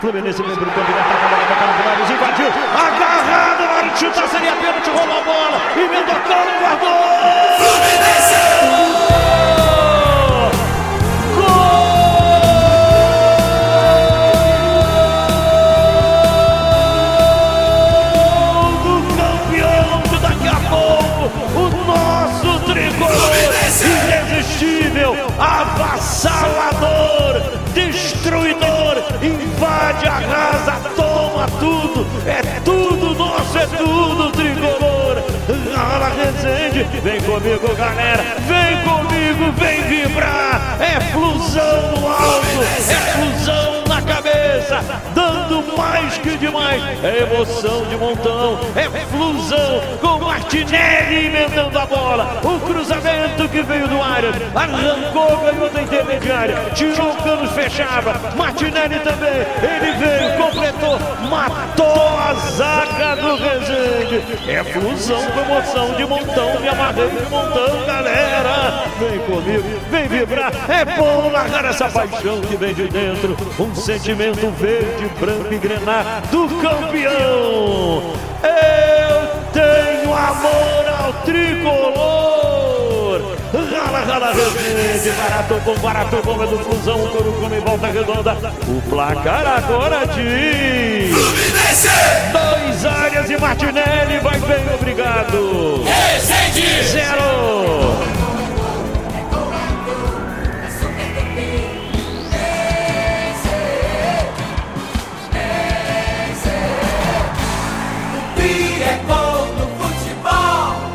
Ouça os gols do título do Fluminense na Taça Guanabara pelo Carioca com a narração de Luiz Penido